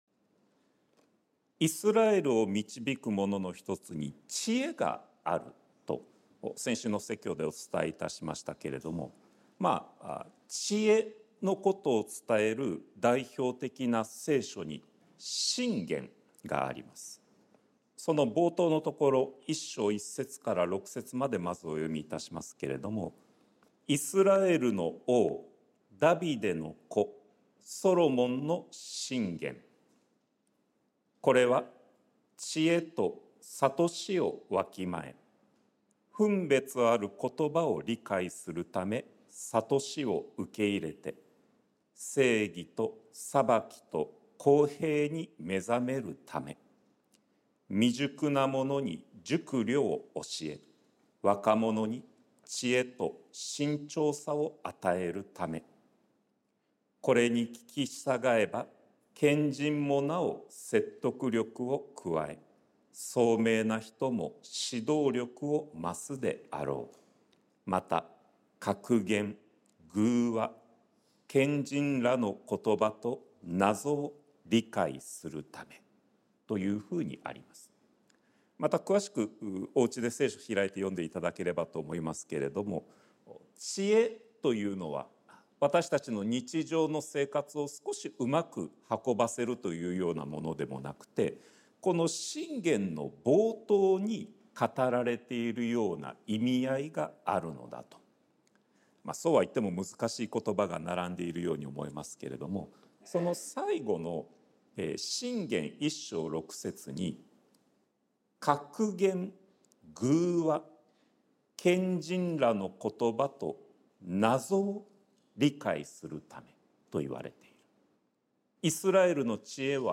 sermon-2024-08-18